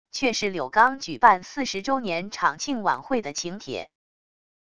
却是柳钢举办四十周年厂庆晚会的请帖wav音频生成系统WAV Audio Player